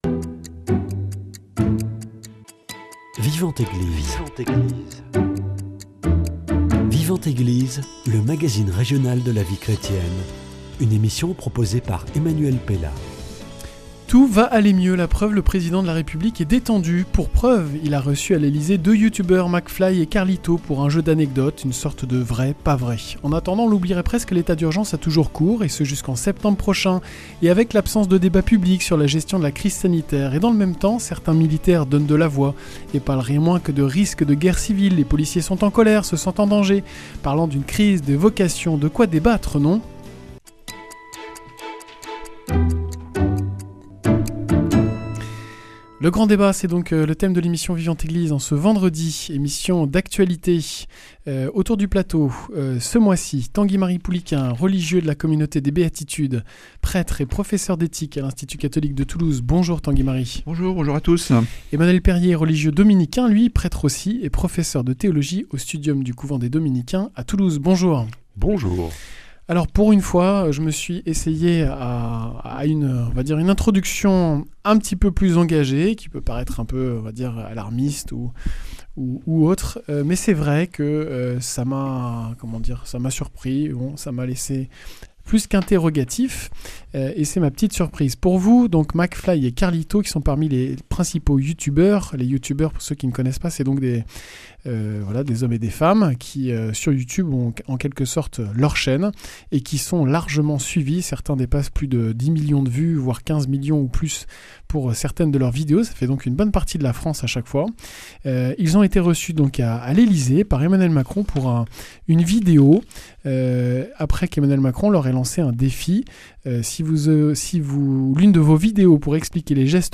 Le grand débat